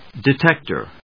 音節de・téc・tor 発音記号・読み方
/dɪˈtɛktɝ(米国英語), dɪˈtektɜ:(英国英語)/